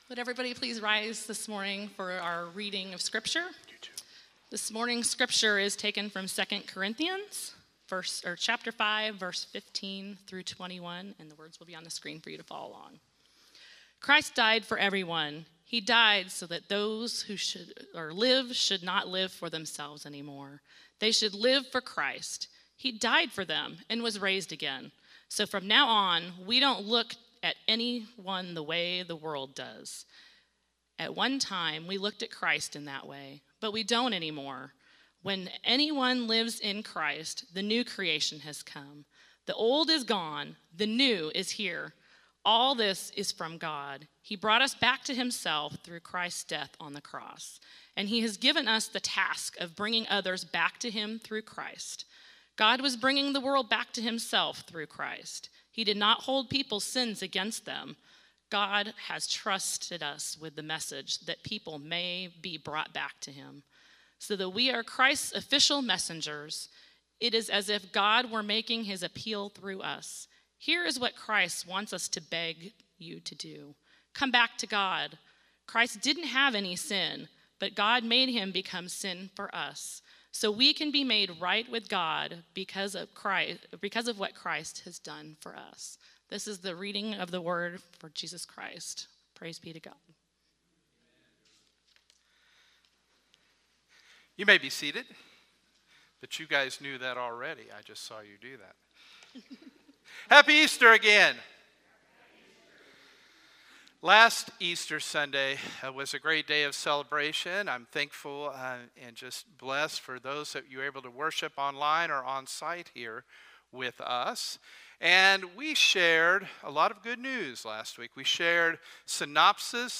Sermons | Harrisonville United Methodist Church